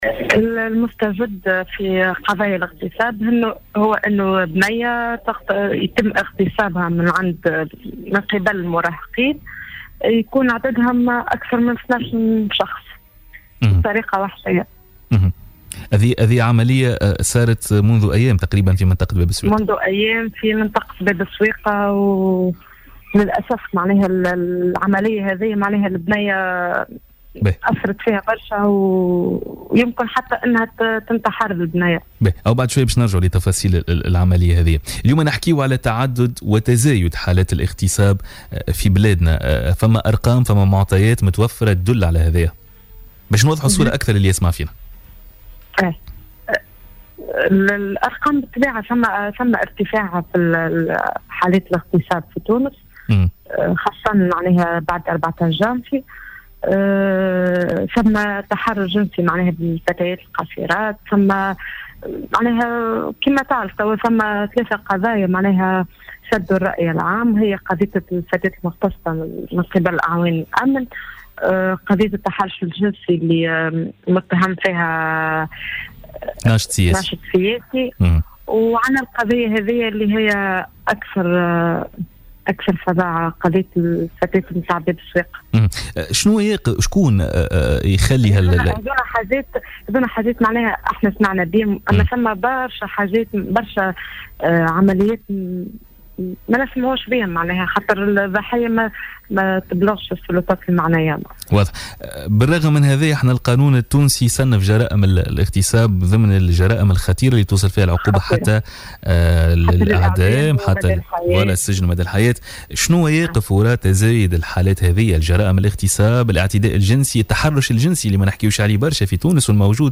مداخلة لها